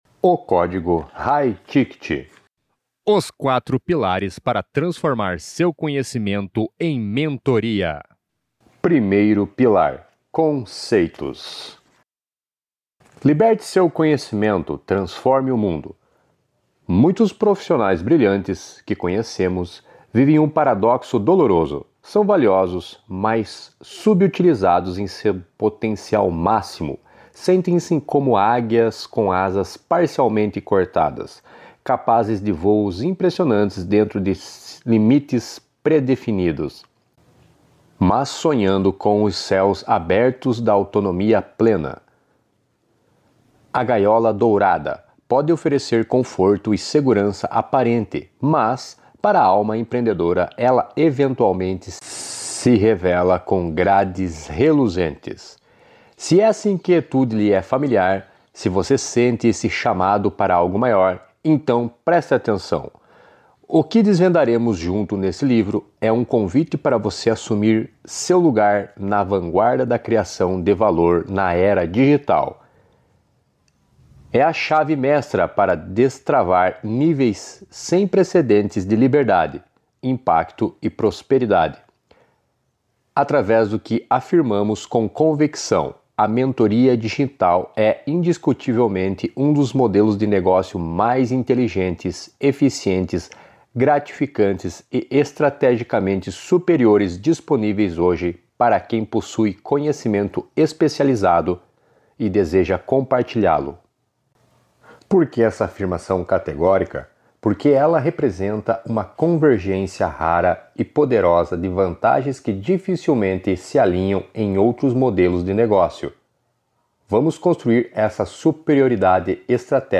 Áudio livro semanal